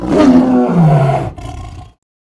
CosmicRageSounds / wav / general / combat / creatures / tiger / he / die1.wav